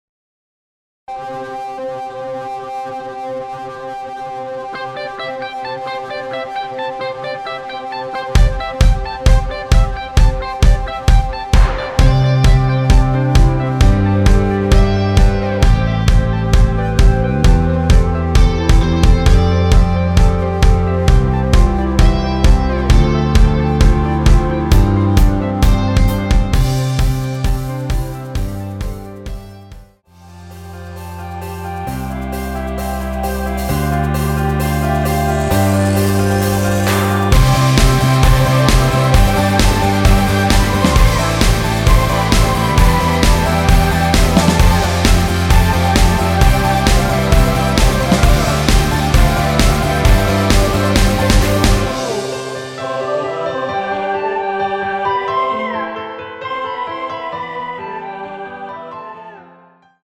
원키에서(+3)올린 멜로디 포함된 MR입니다.(미리듣기 확인)
앞부분30초, 뒷부분30초씩 편집해서 올려 드리고 있습니다.
중간에 음이 끈어지고 다시 나오는 이유는